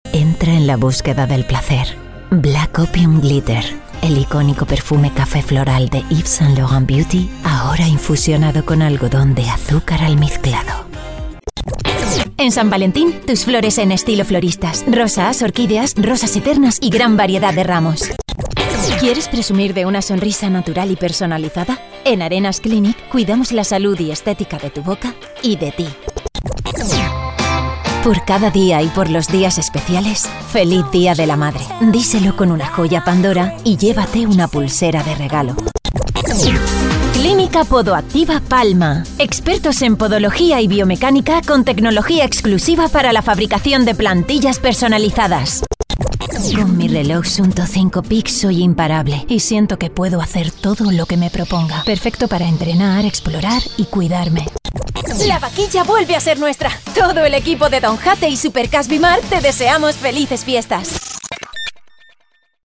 VOZ: 022F
Medias